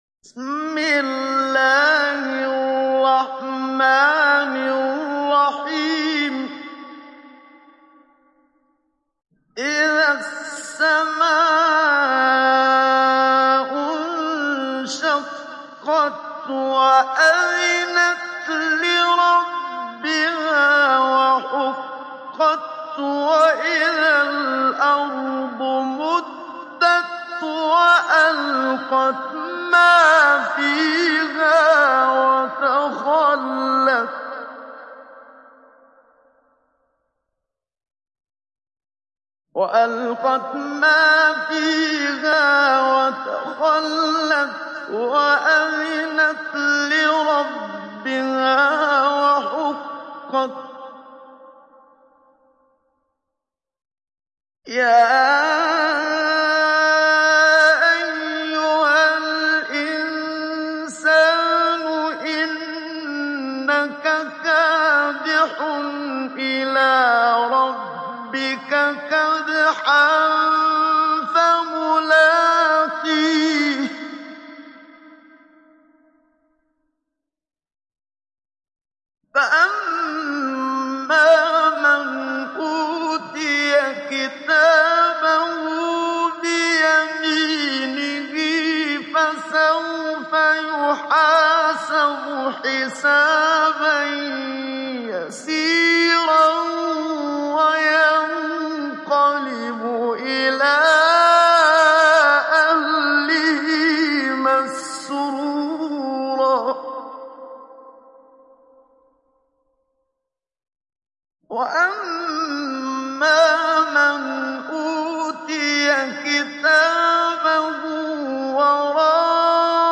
تحميل سورة الانشقاق mp3 بصوت محمد صديق المنشاوي مجود برواية حفص عن عاصم, تحميل استماع القرآن الكريم على الجوال mp3 كاملا بروابط مباشرة وسريعة
تحميل سورة الانشقاق محمد صديق المنشاوي مجود